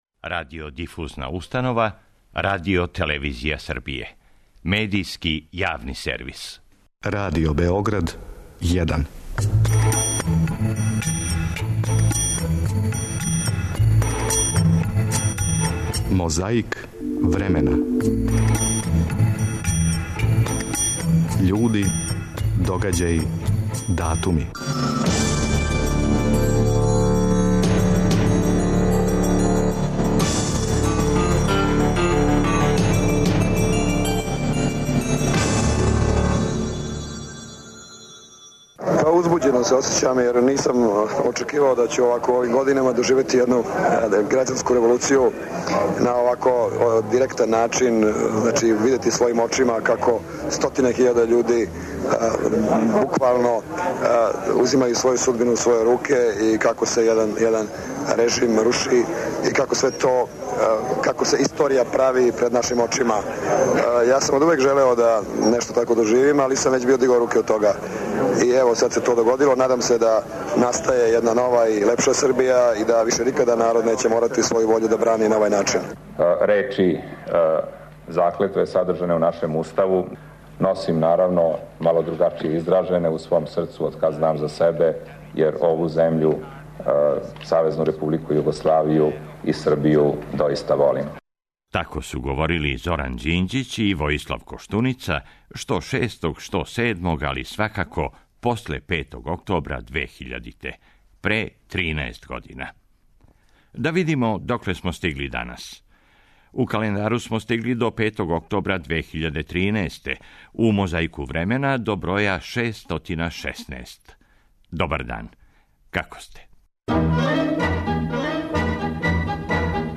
Звучном коцкицом враћамо се тринест година уназад да чујемо шта су говорили Зоран Ђинђић и Војислав Коштуница после 5. октобра 2000. године...
Подсећа на прошлост (културну, историјску, политичку, спортску и сваку другу) уз помоћ материјала из Тонског архива, Документације и библиотеке Радио Београда.